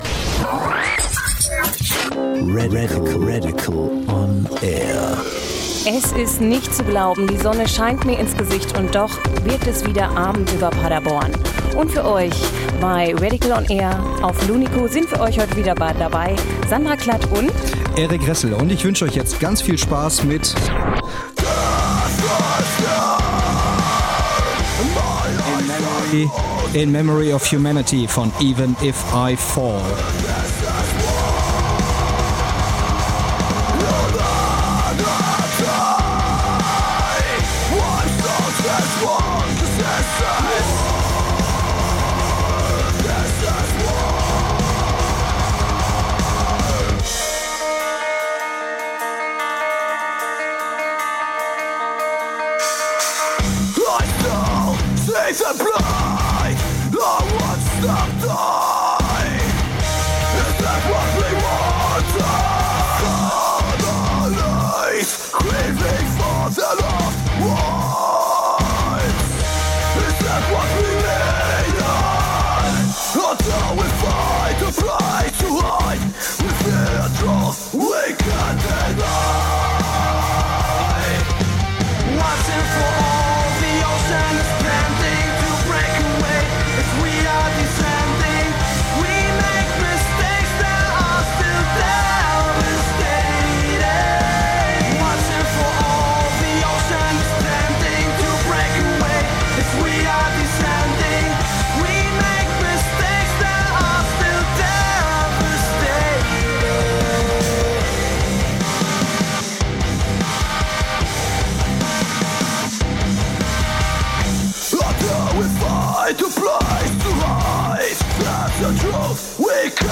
Telefoninterview
im Studio